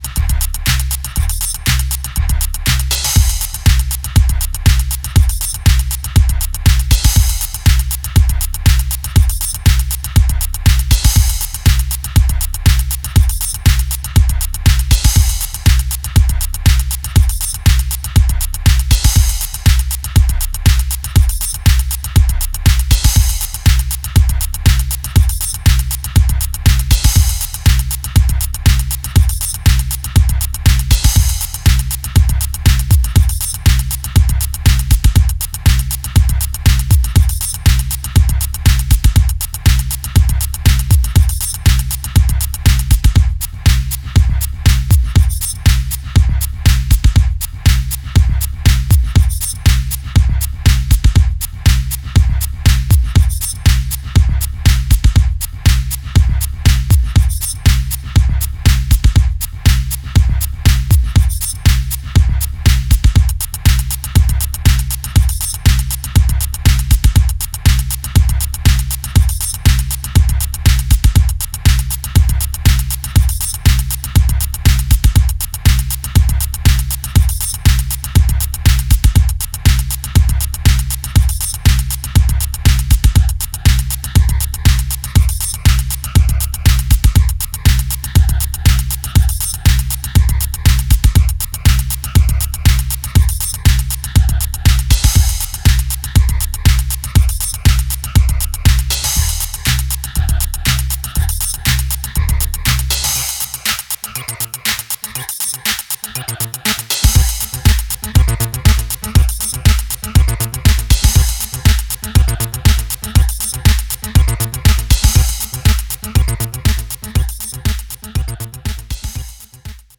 ロボティックな催眠ミニマル・アシッド